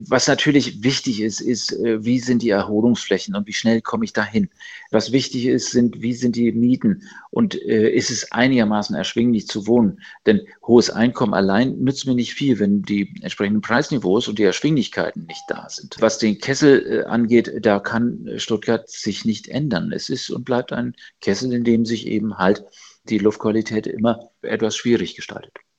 Über den Glücksatlas und wie Stuttgart abgeschnitten hat, sprach SWR4 am Donnerstag, 12.06.2025, mit Bernd Raffelhüschen: